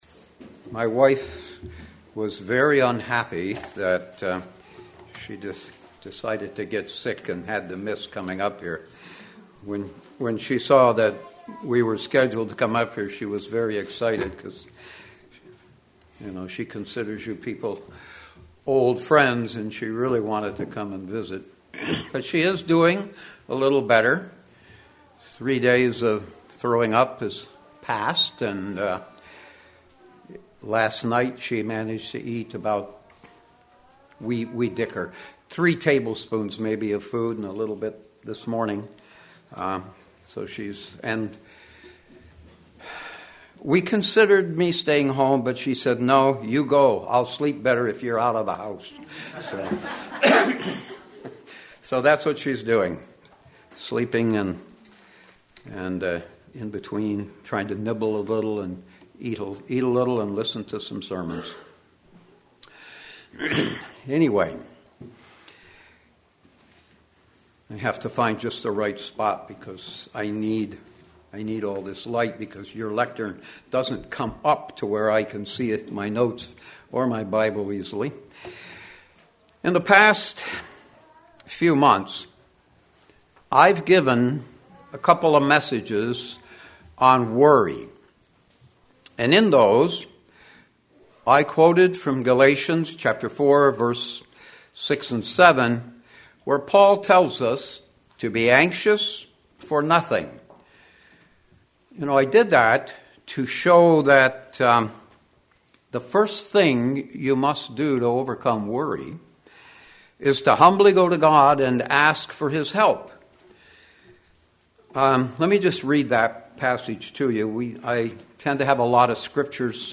Sermons
Given in Flint, MI